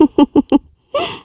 Ripine-Vox_Happy3_kr.wav